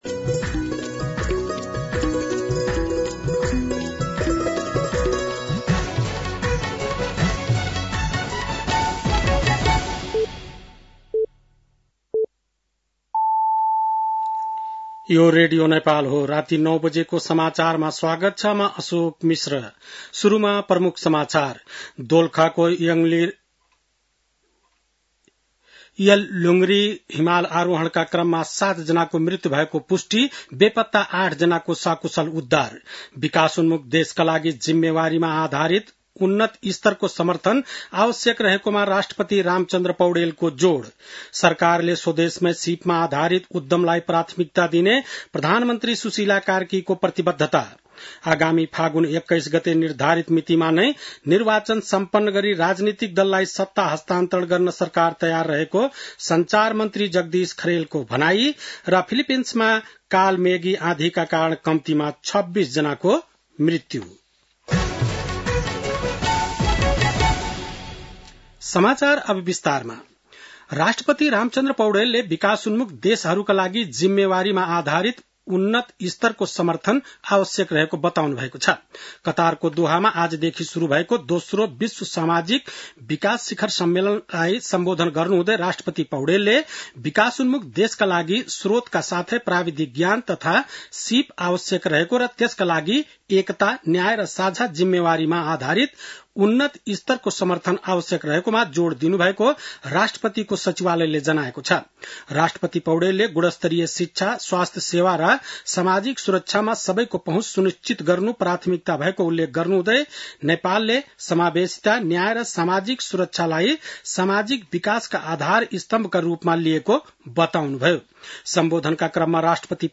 बेलुकी ९ बजेको नेपाली समाचार : १८ कार्तिक , २०८२